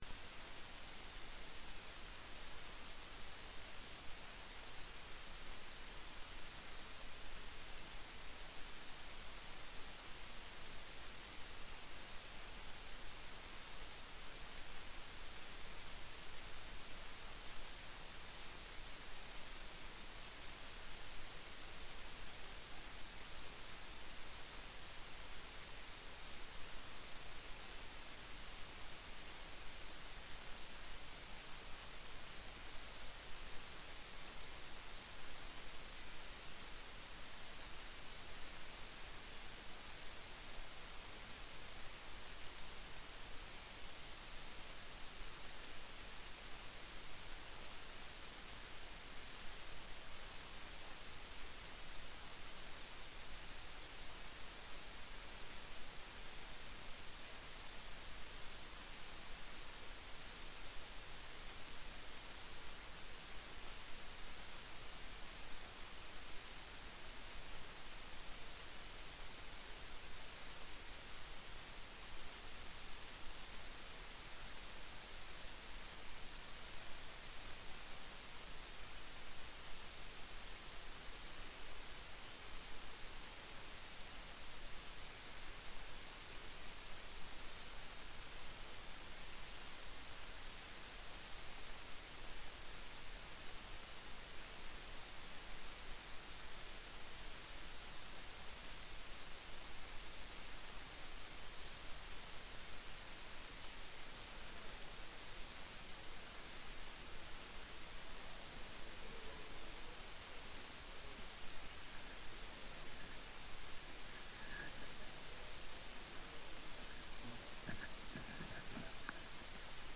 Kjemiforelesning 6
Rom: Store Eureka, 2/3 Eureka